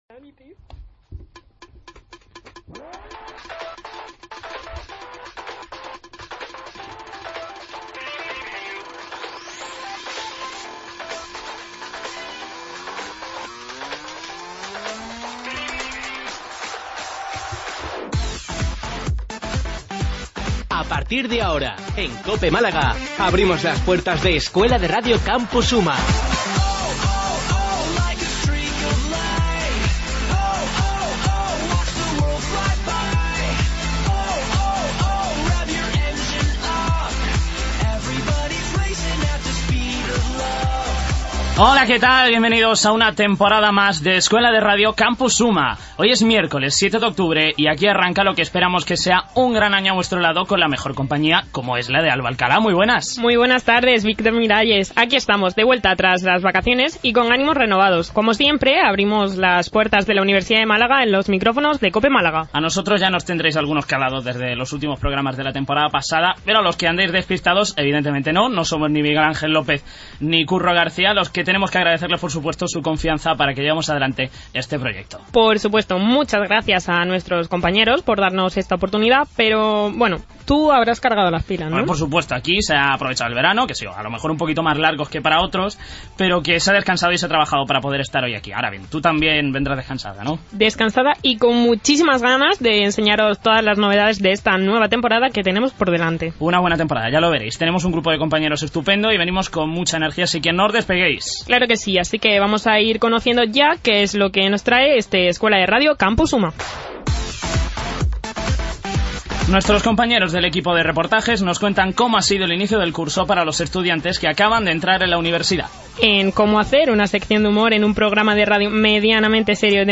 AUDIO: Programa realizado por alumnos de Ciencias de la Comunicación.